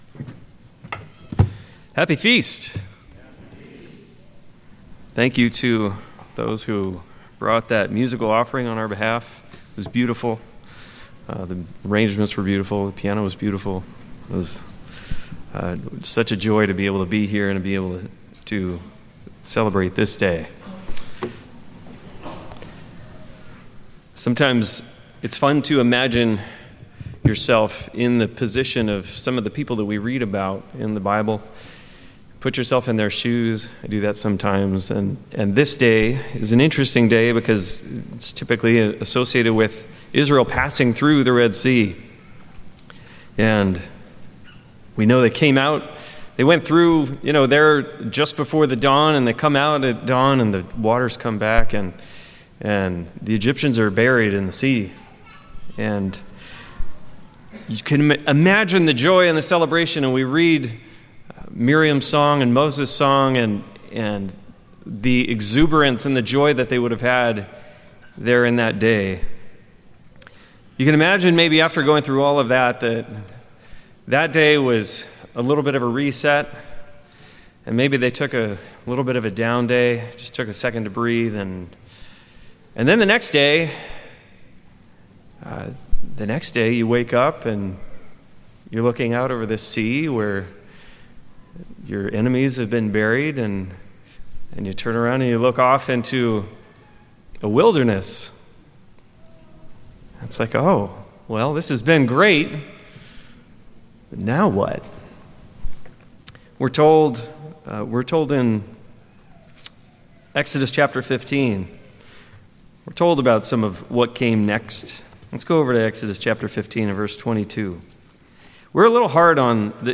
This sermon explores the biblical theme of being “strangers” in this world. Tracing the lives of Abraham, Israel, and even believers today, it shows that God intentionally allows His people to live as outsiders to teach dependence on Him and shape their character.